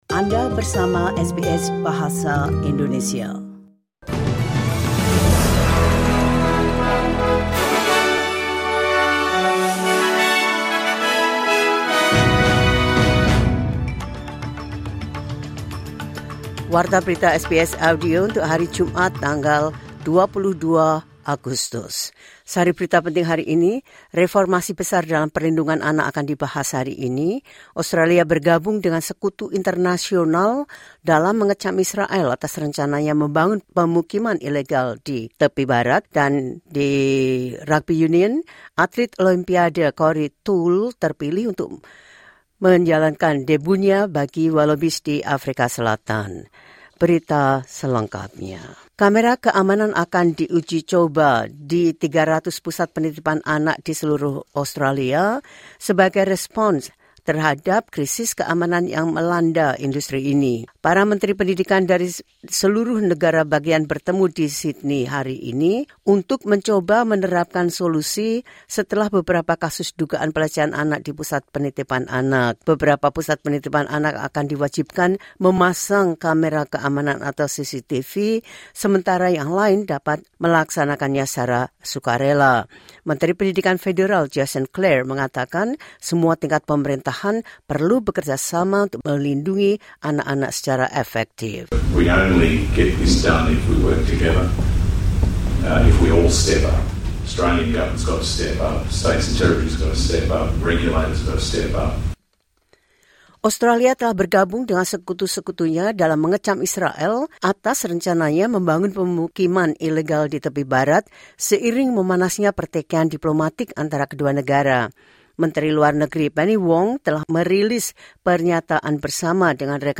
The latest news SBS Audio Indonesian Program – 22 August 2025.